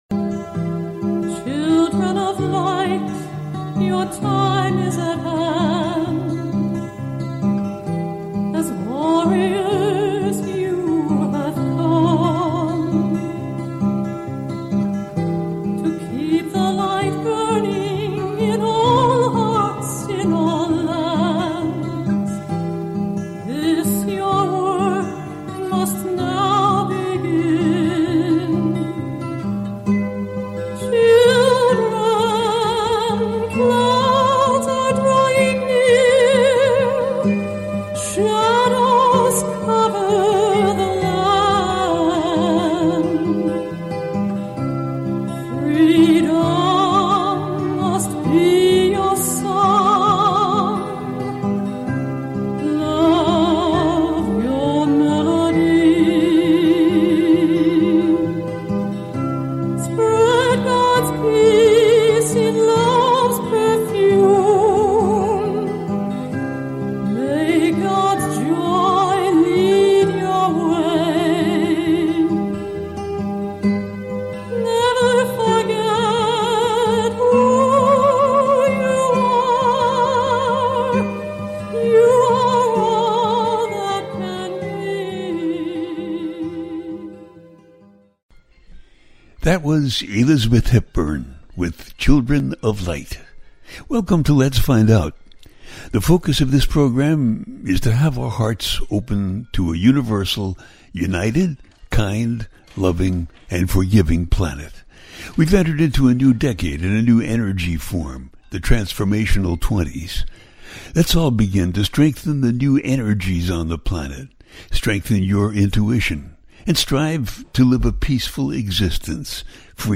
Pisces Lunar Eclipse – The USA Election - A teaching show.